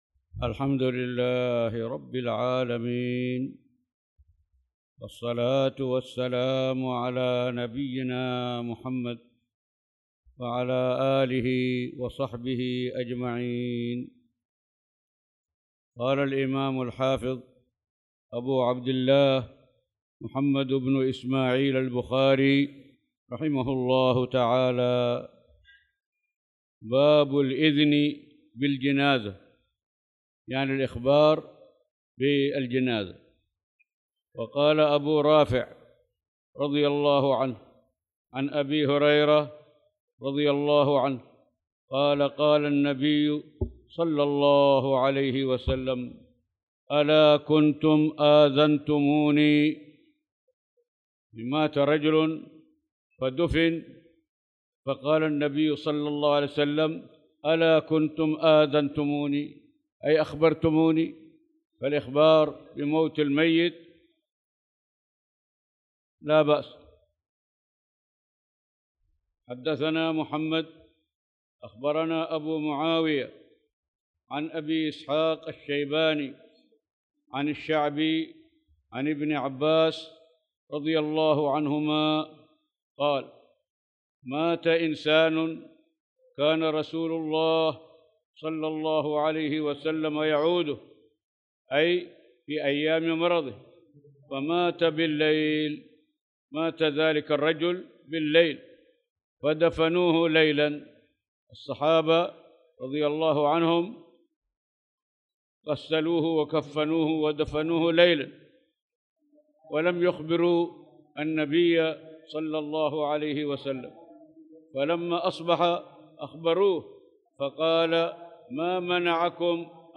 تاريخ النشر ١٤ ذو القعدة ١٤٣٧ هـ المكان: المسجد الحرام الشيخ